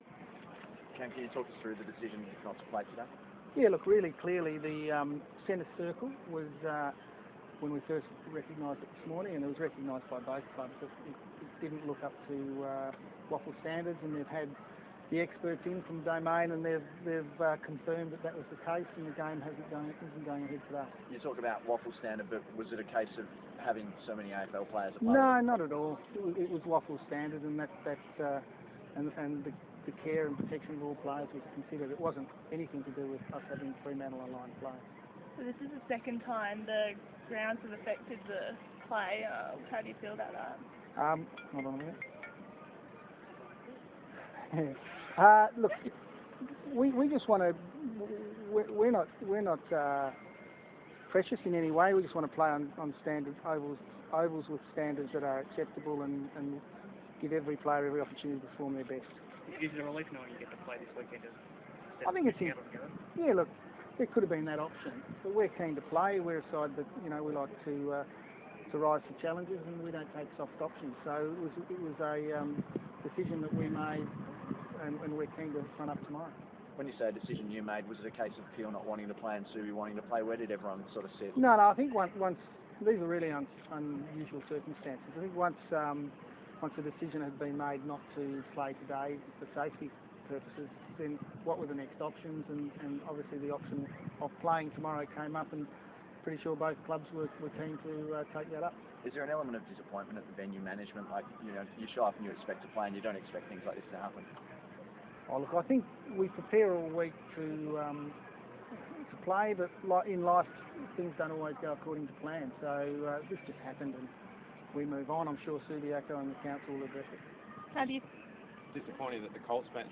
spoke to the media after the Peel v Subiaco game was postponed to Sunday at Domain Stadium due to issues with the centre square